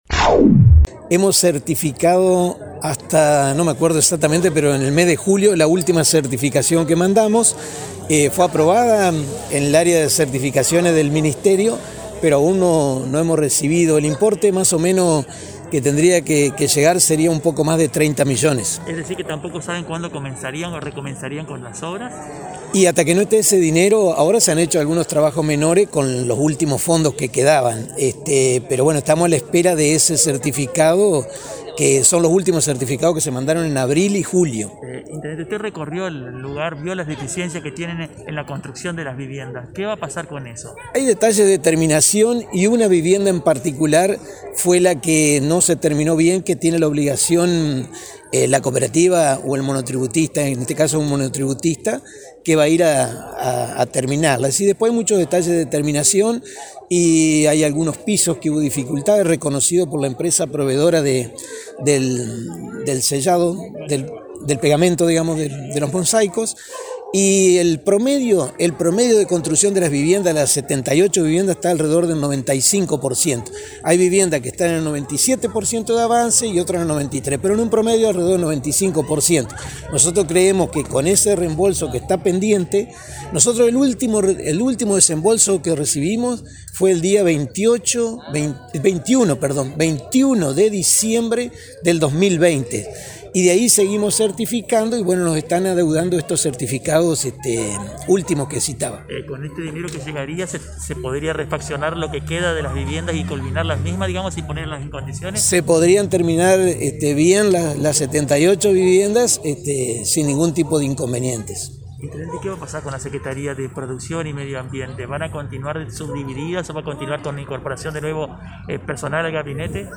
En comunicación con LT39, el intendente Domingo Maiocco confirmó que se han estado realizando trabajos menores en el predio conocido como Chacra Don Gregorio, pero que, para poder avanzar y finalizar la obra, se necesita que el gobierno nacional realice el desembolso de la partida ya presupuestada.